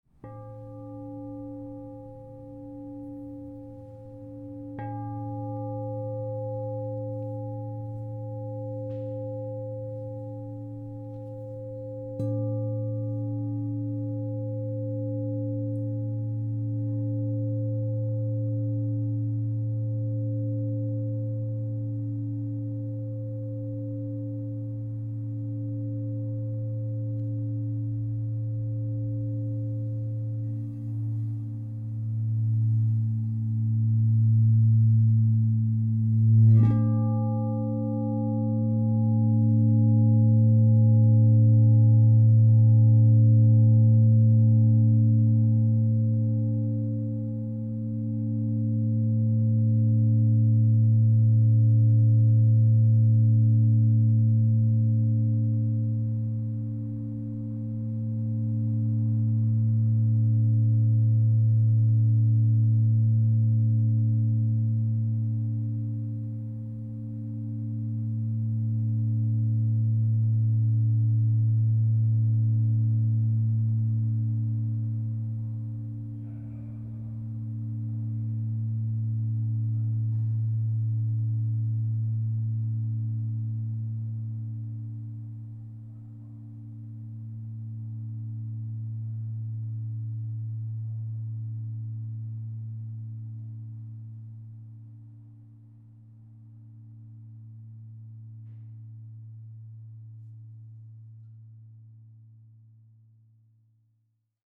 16″ A -20 SKU: 116259